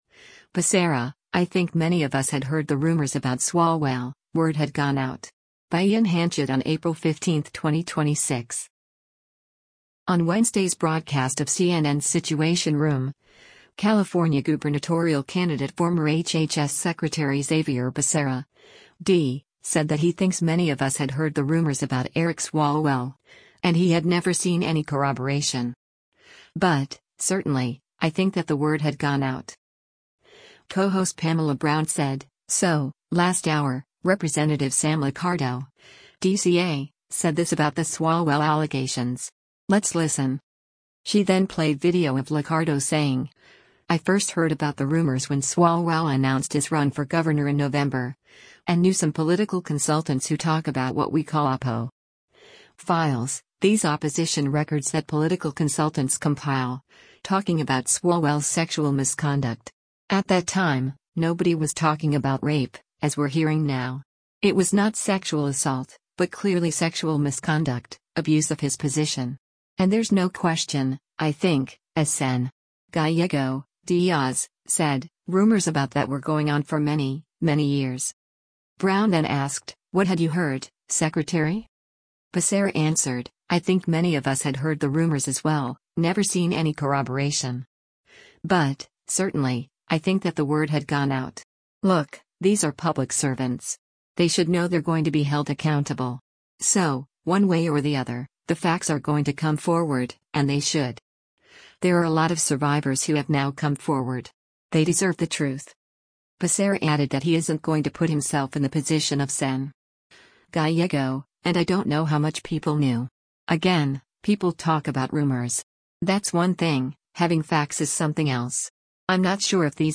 On Wednesday’s broadcast of CNN’s “Situation Room,” California gubernatorial candidate former HHS Secretary Xavier Becerra (D) said that he thinks “many of us had heard the rumors” about Eric Swalwell, and he had “never seen any corroboration. But, certainly, I think that the word had gone out.”